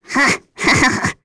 Nia-Vox_Happy1.wav